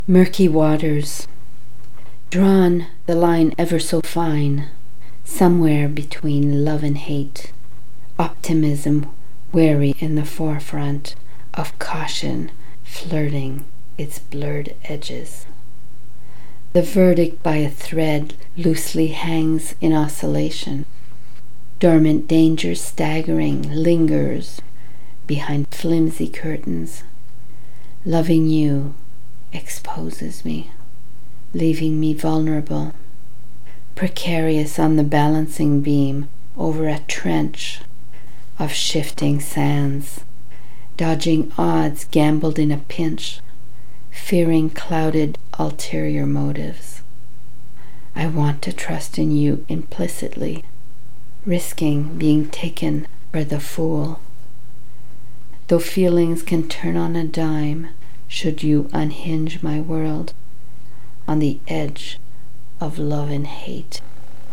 so much emotion expressed with your spoken words ~ truly superb...